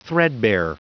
Prononciation du mot threadbare en anglais (fichier audio)
Prononciation du mot : threadbare